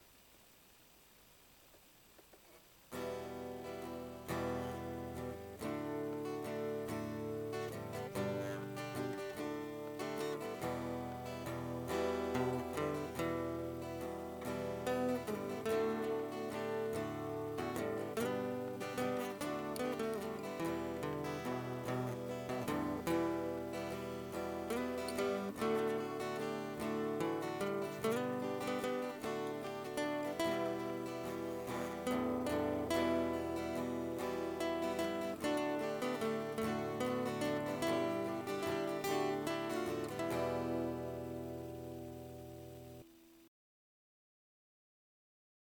la voici rejouée de mémoire